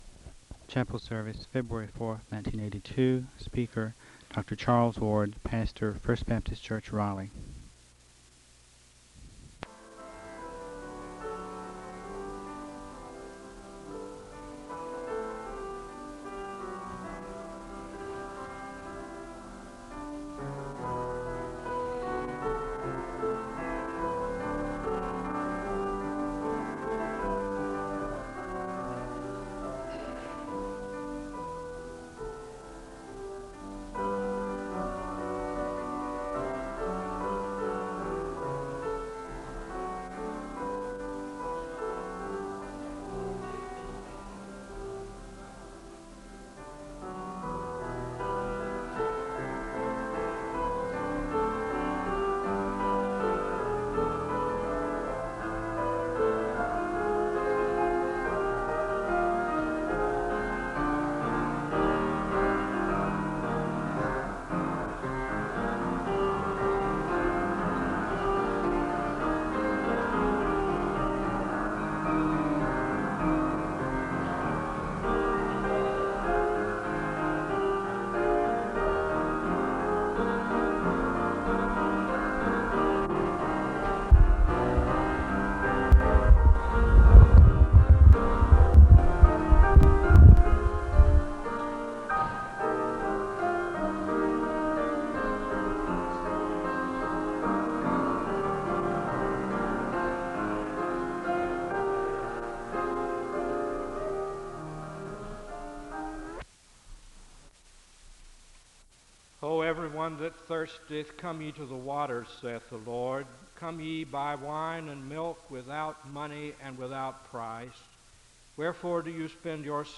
Audio quality is poor.
The service begins with piano music (00:00-01:47). The speaker gives a word of prayer (01:48-04:25).
The choir sings a song of worship (06:21-08:50).